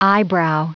Prononciation du mot eyebrow en anglais (fichier audio)
Prononciation du mot : eyebrow